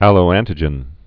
(ălō-ăntĭ-jən)